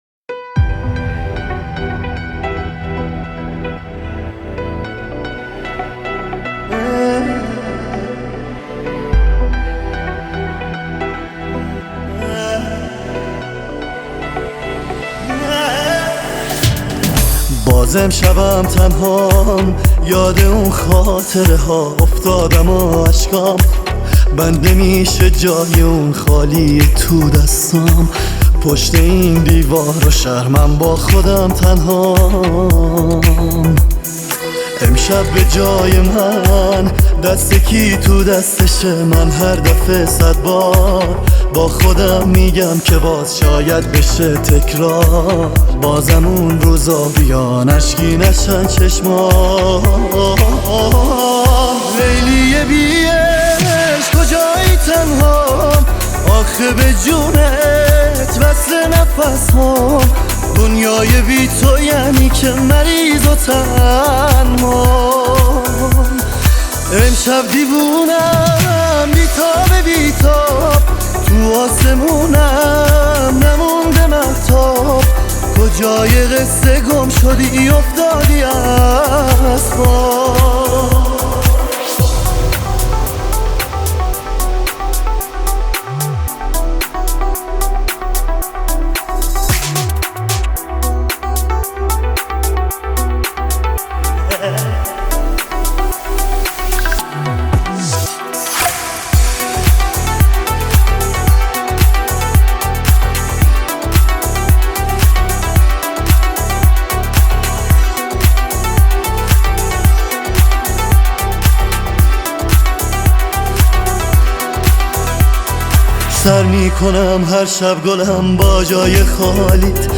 این آهنگ فارسی رو قانونی و انحصاری از کانال ما بشنوید